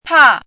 chinese-voice - 汉字语音库
pa4.mp3